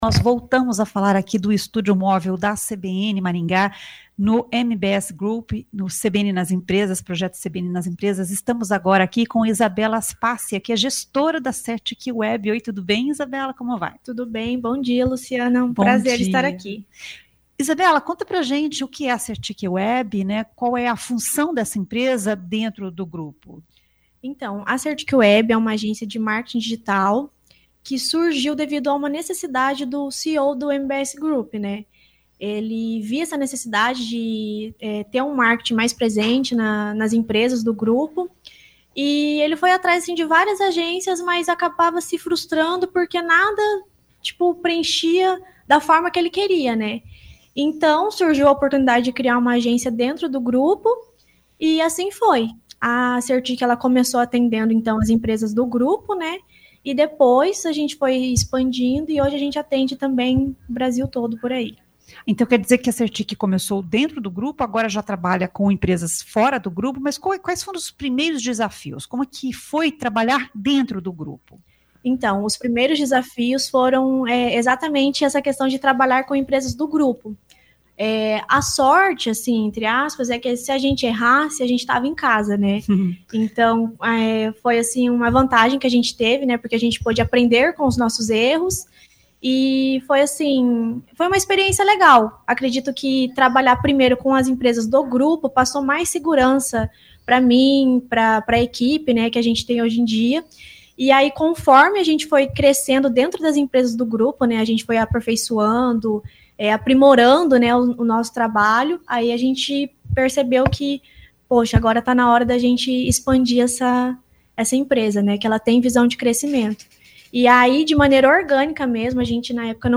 A entrevista foi realizada no estúdio móvel instalado na sede do MBS Group, dentro do projeto CBN nas Empresas.